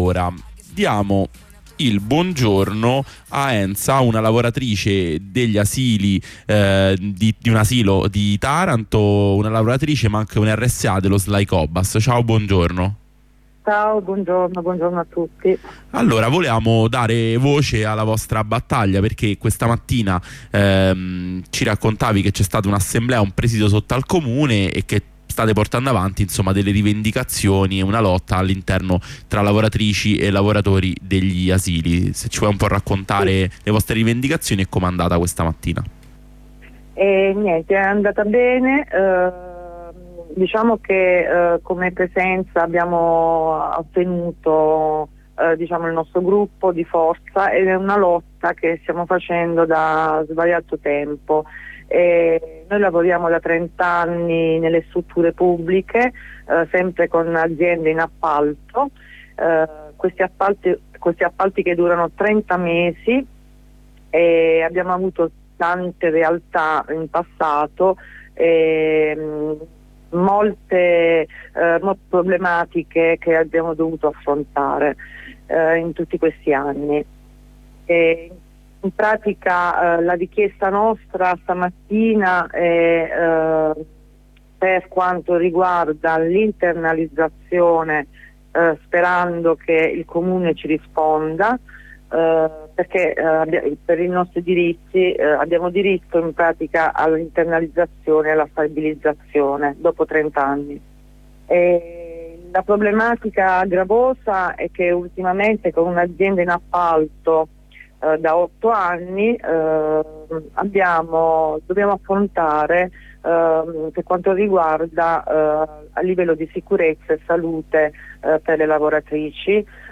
In una lunga corrispondenza con due lavoratrici degli asili di Taranto, RSA e RLS dello SLAI COBAS, analizziamo i punti della loro piattaforma di lotta come lavoratrici esternalizzate dei servizi degli asili pubblici.